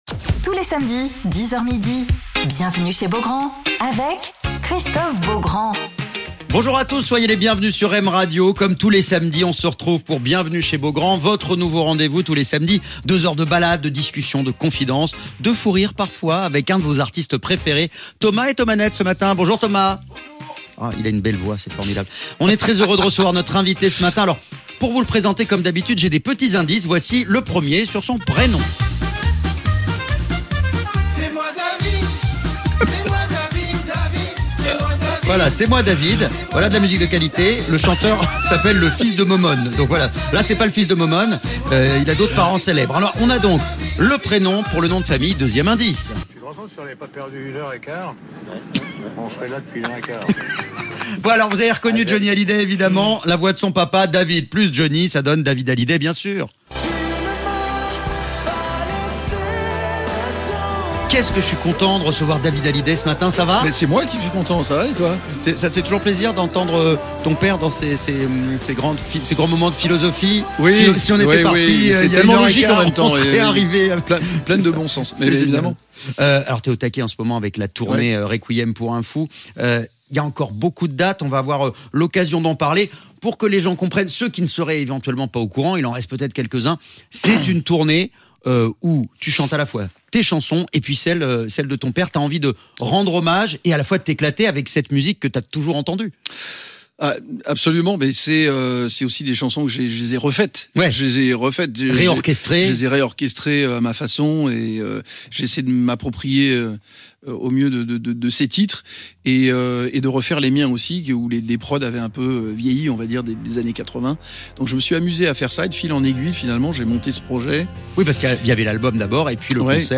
Alors qu'il est en tournée avec "Requiem pour un fou", David Hallyday est l'invité de Christophe Beaugrand sur M Radio